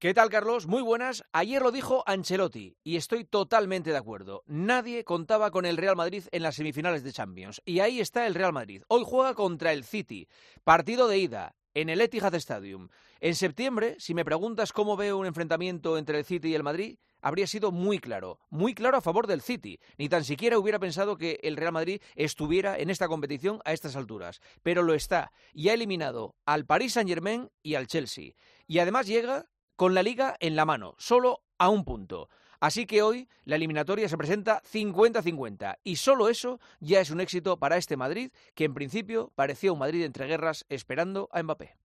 Juanma Castaño analiza la actualidad deportiva en 'Herrera en COPE'